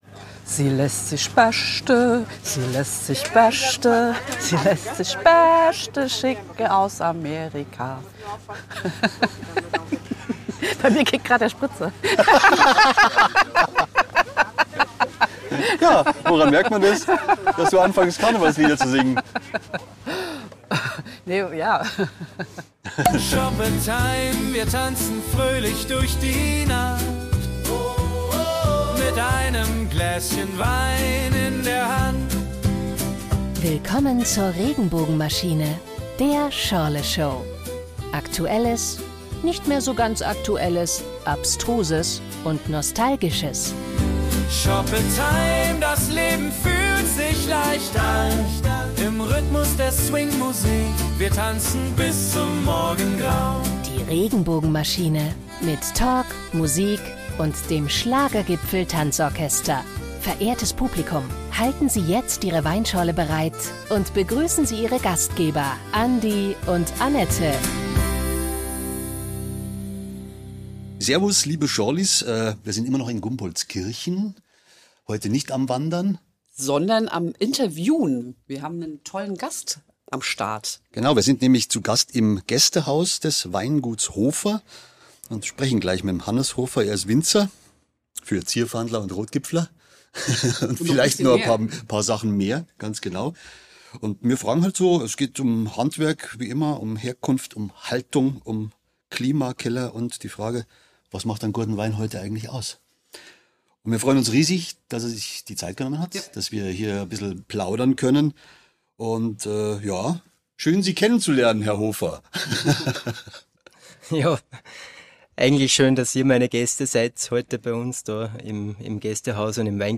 Teil 2 unseres Gumpoldskirchen-Abenteuers! Ein Winzer im Interview, Wein en masse auf der Genussmeile, und später Heurigenstimmung mit Musik, Schmäh und Zierfandler.